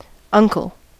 Ääntäminen
IPA: /ˈse.tæ/